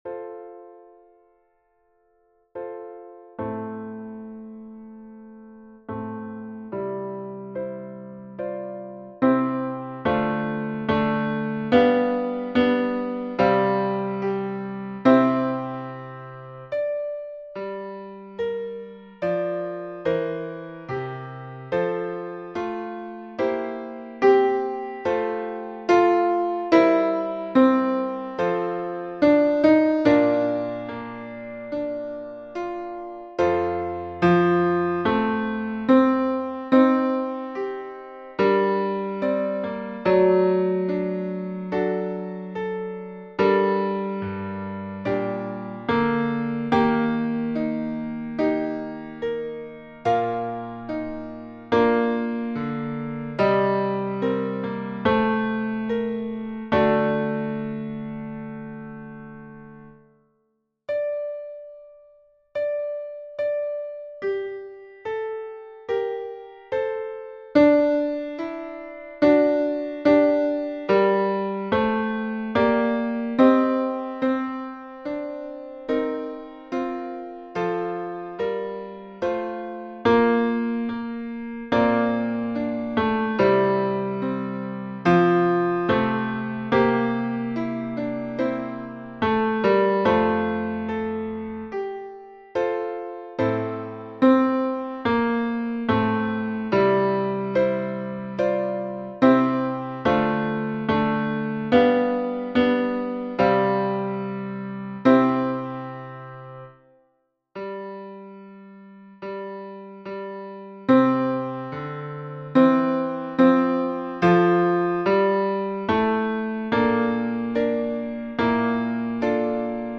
Tenor 2